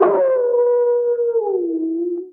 bdog_howl_2.ogg